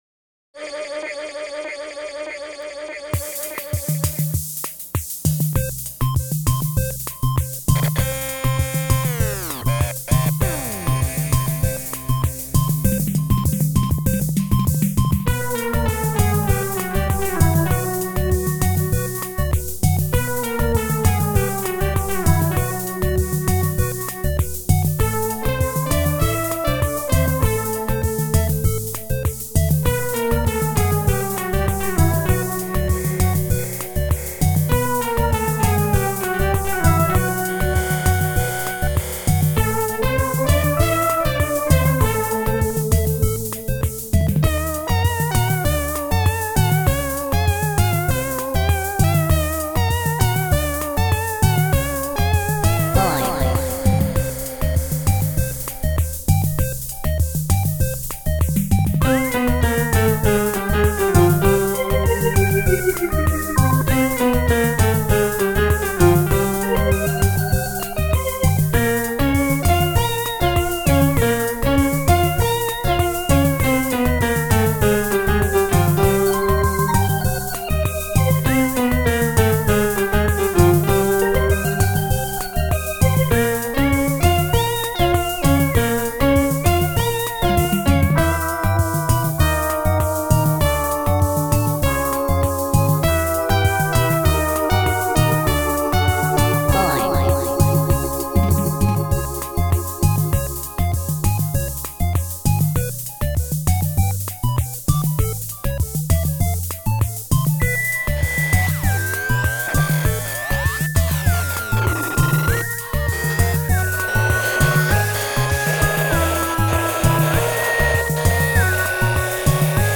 It's simple, immediately playable, and annoying.
Of course those rules are supposed to be broken, and it can sound like a fun space ship. The guts come from circuits of two identical toy ray guns that have been declocked and routed to the body contacts.